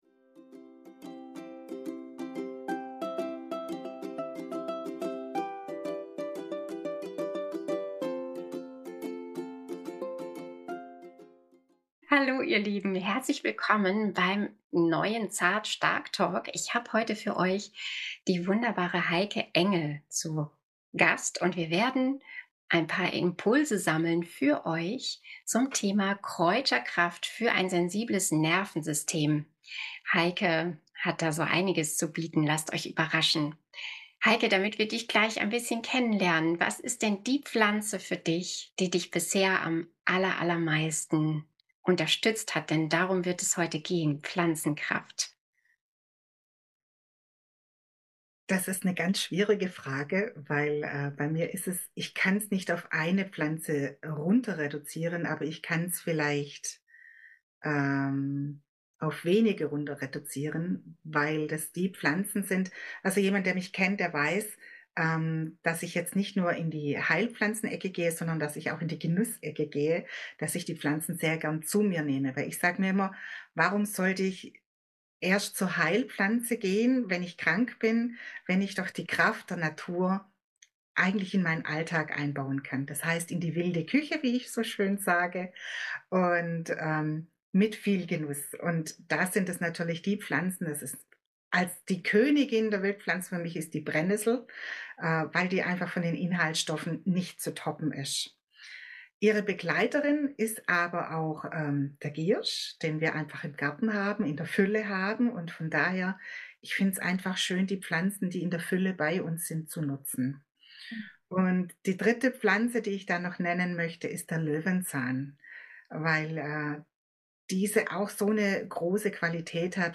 DAS E-BOOK ZUM INTERVIEW